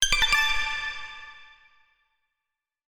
Ice Reflect.wav